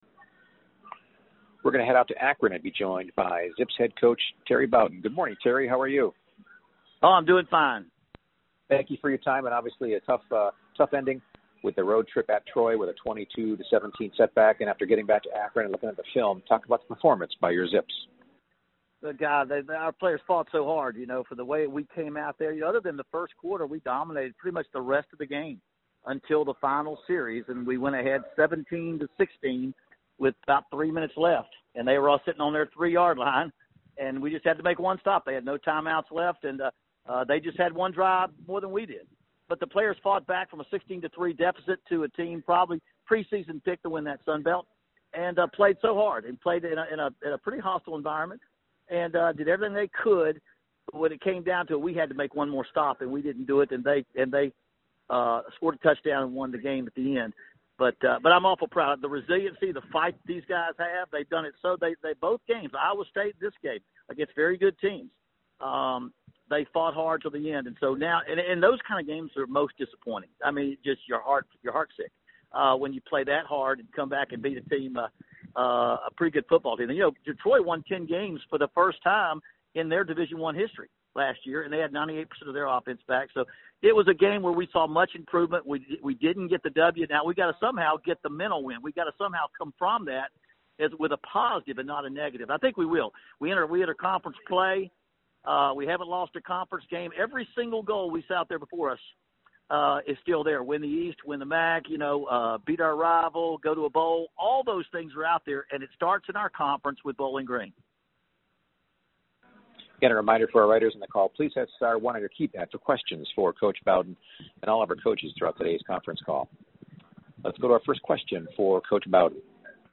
MAC Weekly Media Teleconference Audio (Bowden)